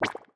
SFX_Slime_Hit_V2_04.wav